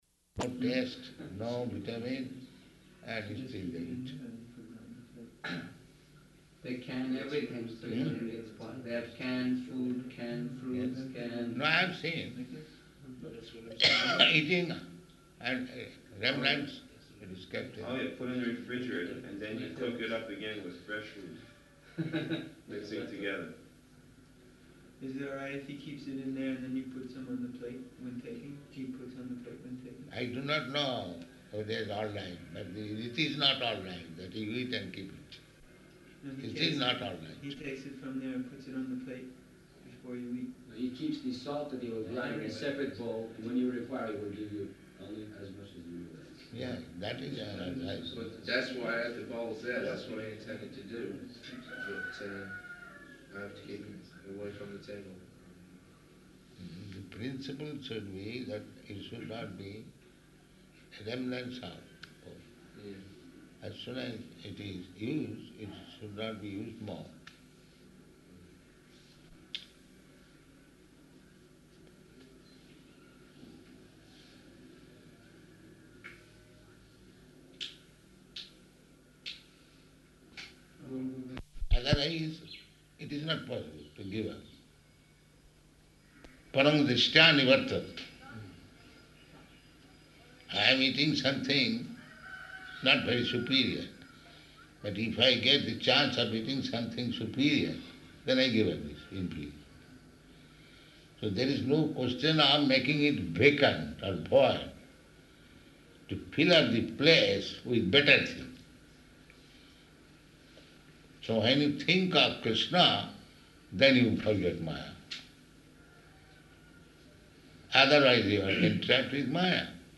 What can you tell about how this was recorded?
Location: Nellore